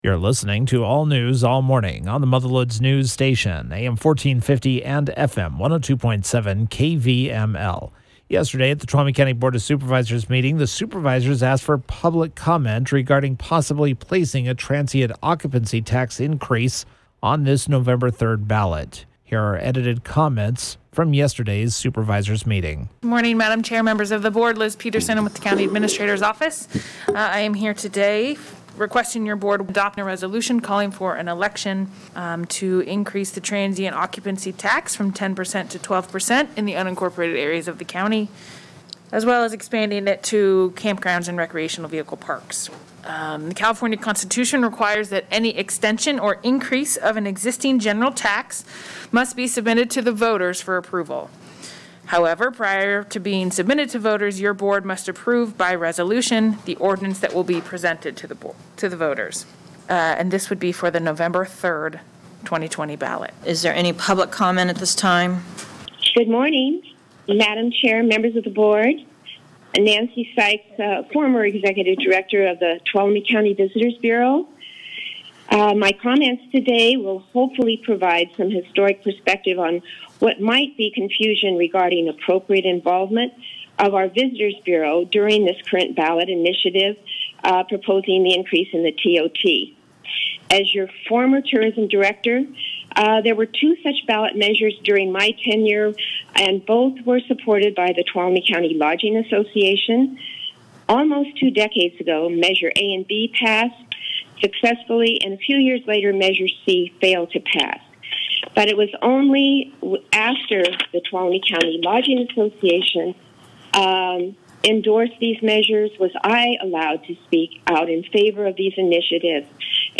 Here are edited comments from yesterday’s Supervisor meeting: